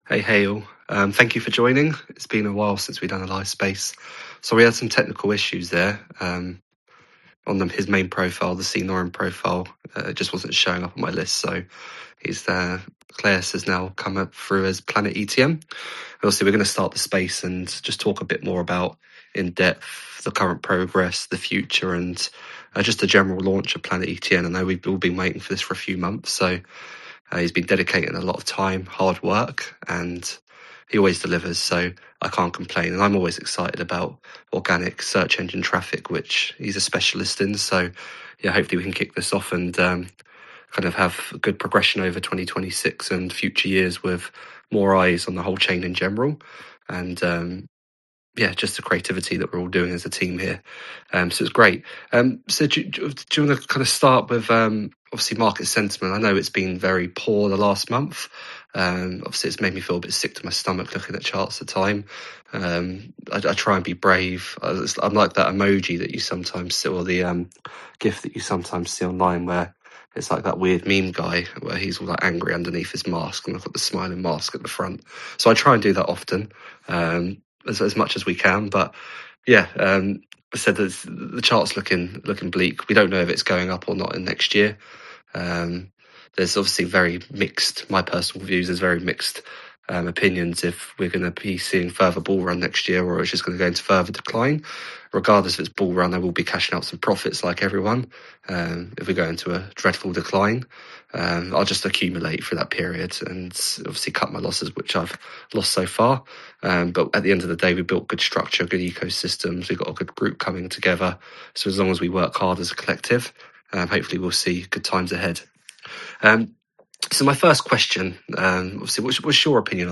The full Planet ETN launch conversation is now available to listen to here (play or download mp3). Hear the story behind the project, thoughts about the future, and the community vision shared during the launch Space.